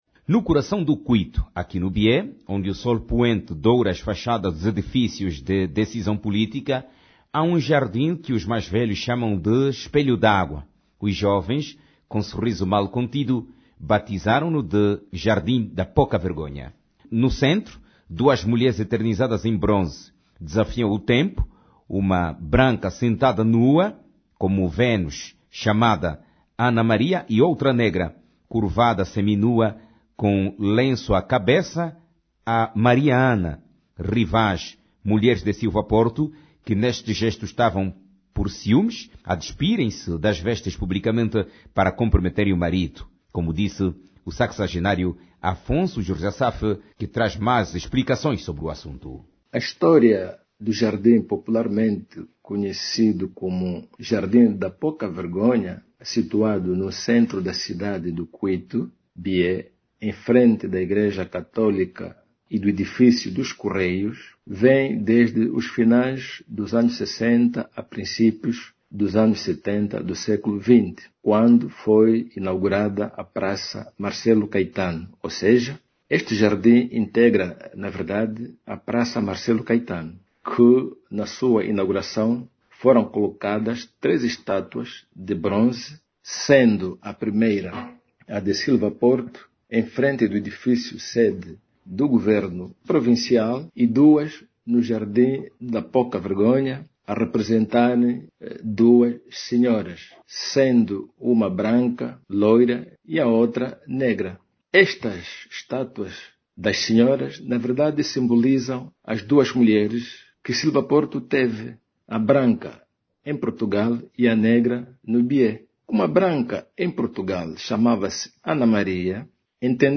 O Jornal de Sábado da Rádio Nacional de Angola, levou os ouvintes a conhecerem através das ondas artesianas, a história do mediático Jardim da Pouca Vergonha ou se preferirem o Jardim Espelho d´Água. Era ou é para uns ainda, o Jardim da Pouca Vergonha por causa de estátuas de duas mulheres nuas que se encontram no local.